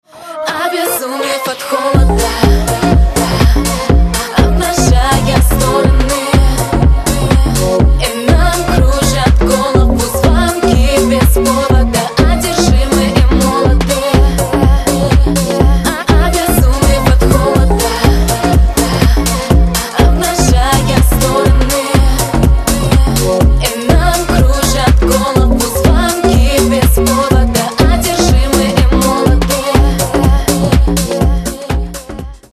• Качество: 128, Stereo
поп
dance